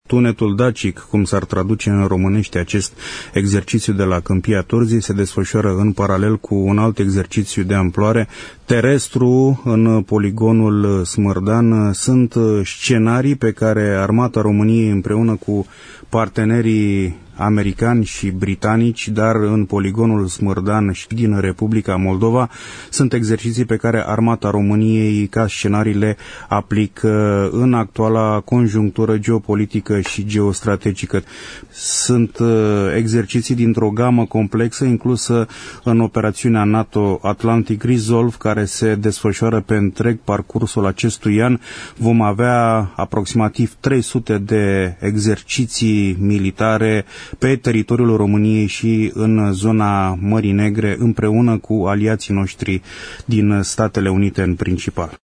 prezent la emisiunea „Pulsul Zilei”, despre însemnătatea exerciţiilor de apărare: